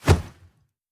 Throw_ObjectSwoosh.wav